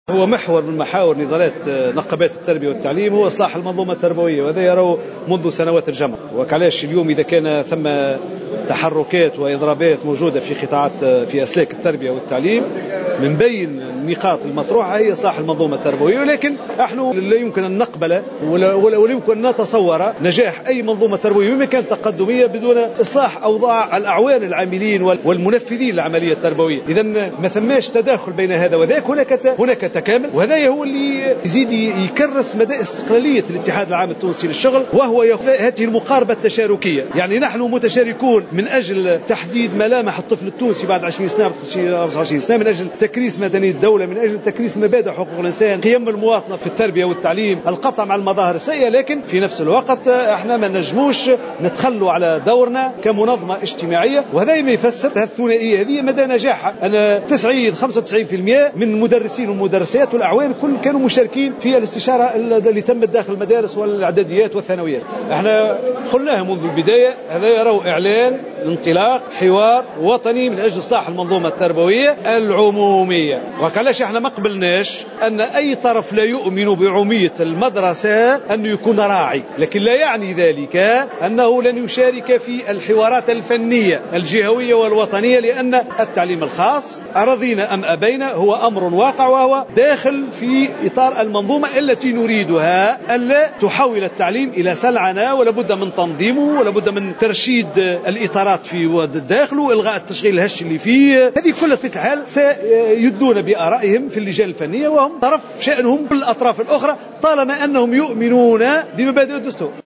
تصريح لمراسل جوهرة أف أم